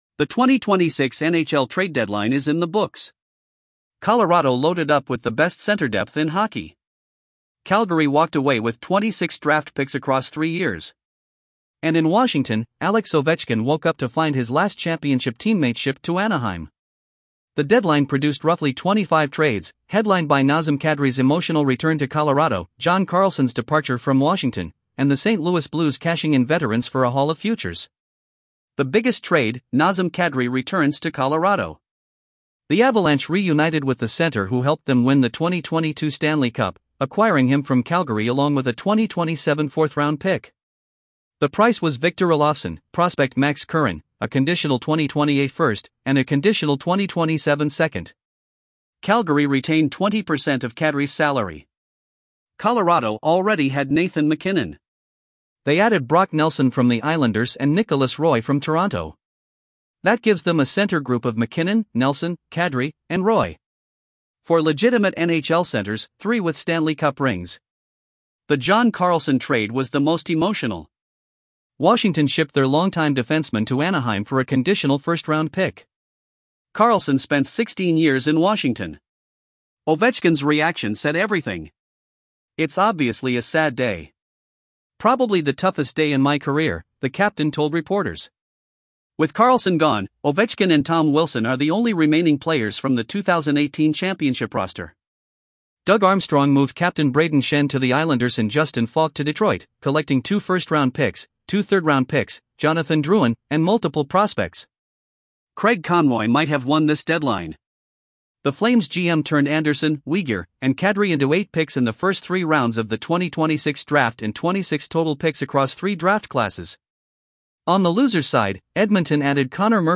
AI Voice
article-19-tts.mp3